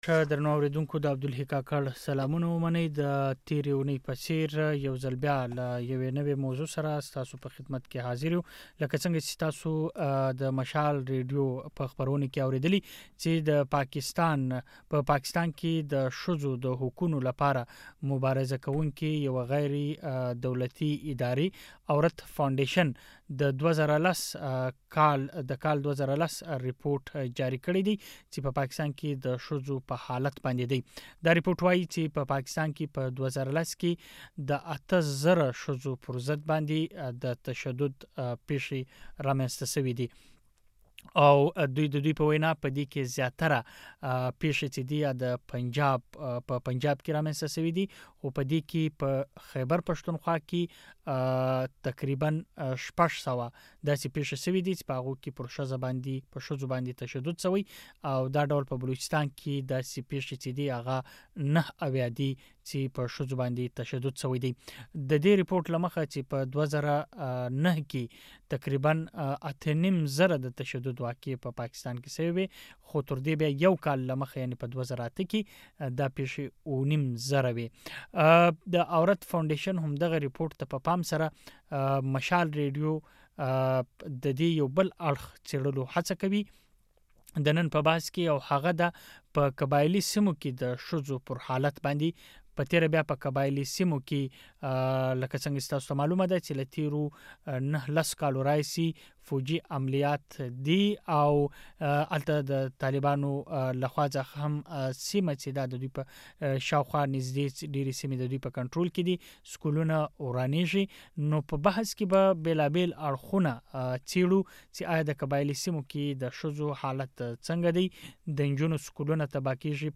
د عورت فاونډيشن رپورټ ته په پام سره مشال ريډيو په قبائلي سيمو کې د ترهګری پر ضد تر جګړي مخکې اووروسته د ښځو حالت د بحث موضوع ګرځولی دی.